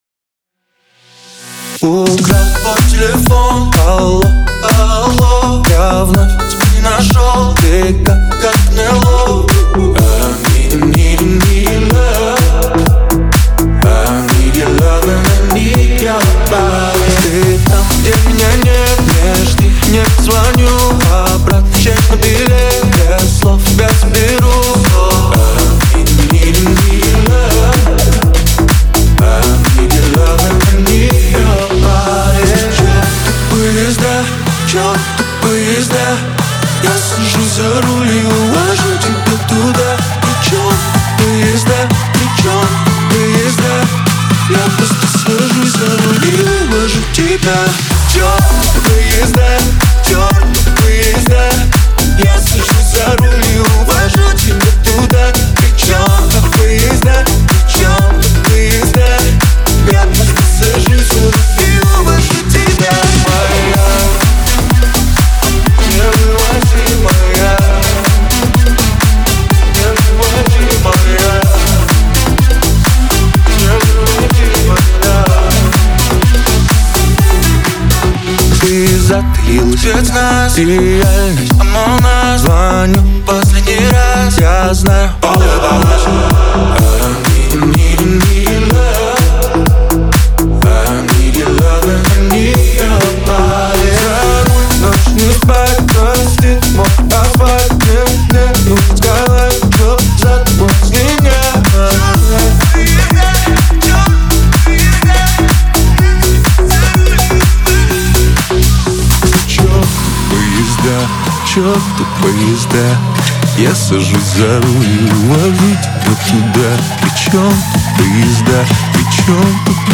это энергичная композиция в жанре поп с элементами рэпа